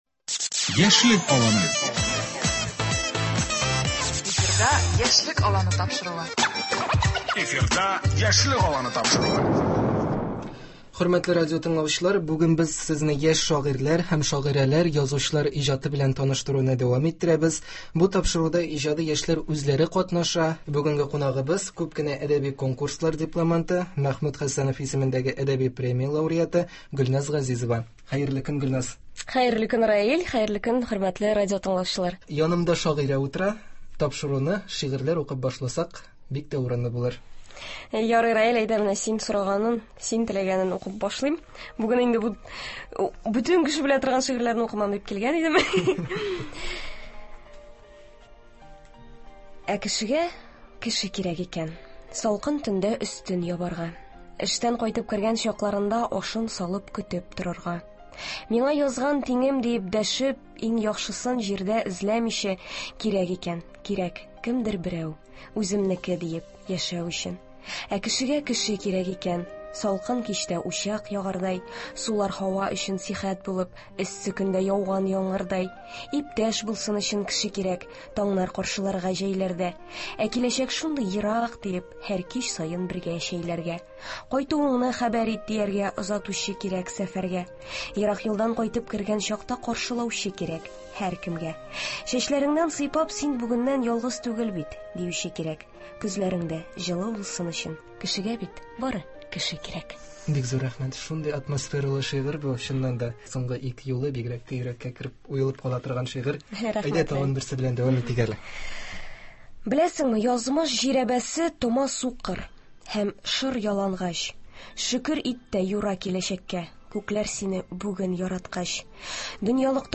үген без Сезне яшь шагыйрьләр һәм шагыйрәләр, язучылар иҗаты белән таныштыруны дәвам иттерәбез. Бу тапшыруда иҗади яшьләр үзләре катнаша һәм бергә әңгәмә кору өчен турыдан-туры тапшырулар студиясенә киләләр.